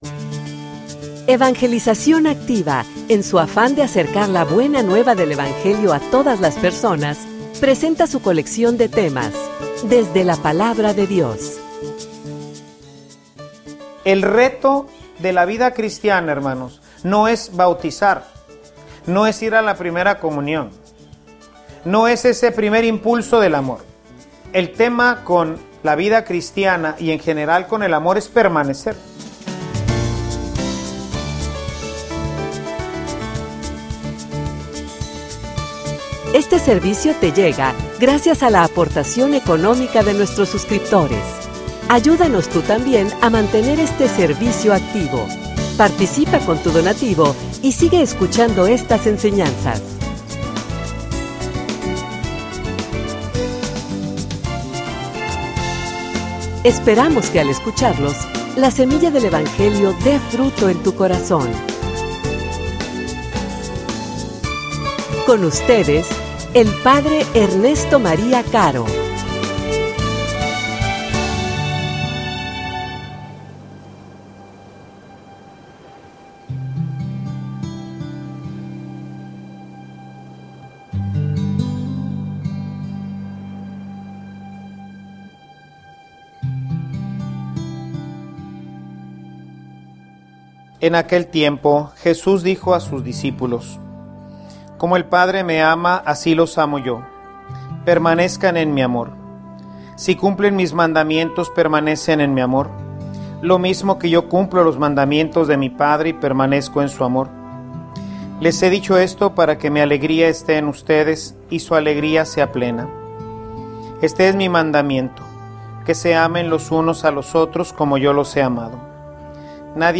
homilia_Servicio_que_alimenta_el_amor.mp3